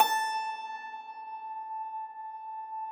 53k-pno17-A3.aif